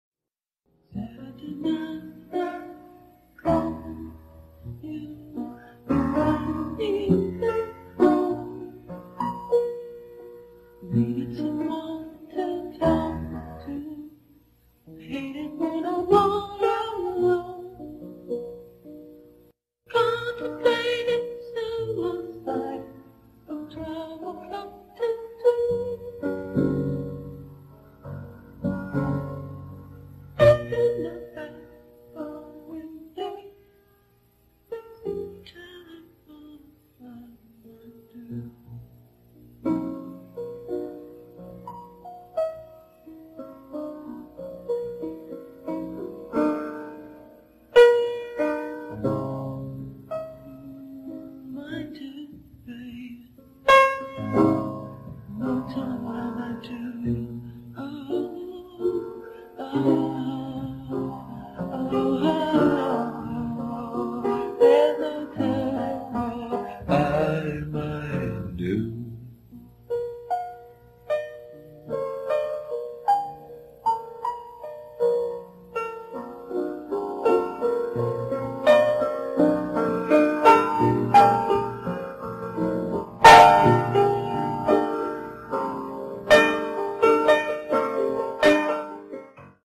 in his home studio